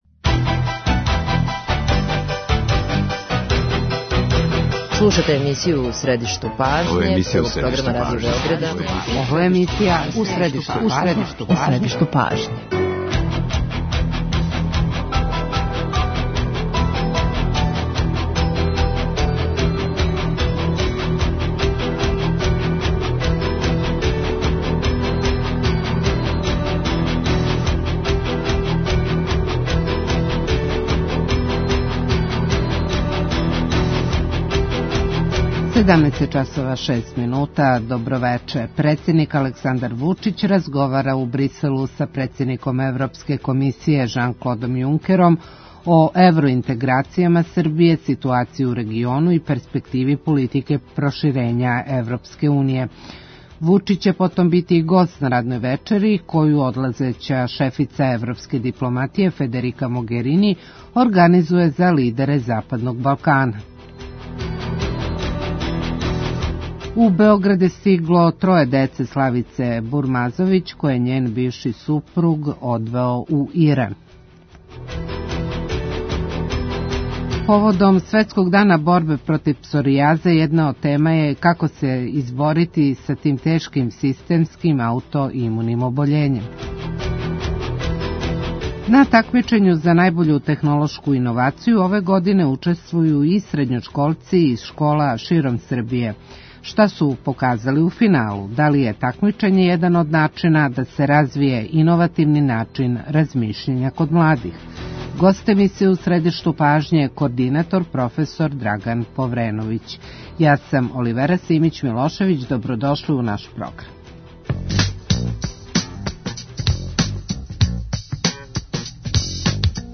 Шта још треба учинити да би се свест о томе проширила, пре свега у млађој генерацији?У разговору учествују директори школа и ментори тимова који су дошли до финала Такмичења за најбољу технолошку иновацију.